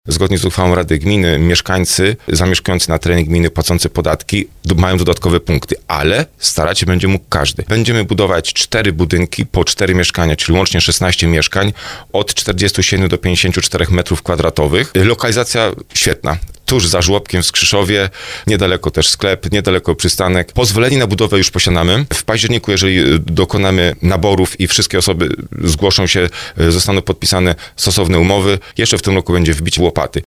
Wójt gminy Skrzyszów Marcin Kiwior mówił w audycji Słowo za Słowo, że o mieszkanie może starać się każdy, ale pewne preferencje będą mieli mieszkańcy gminy.